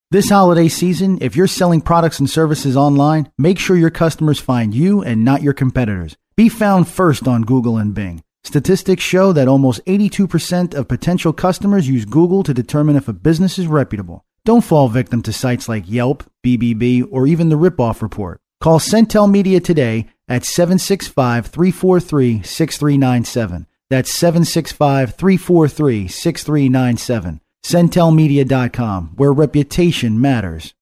Centel Media Holiday Radio Ad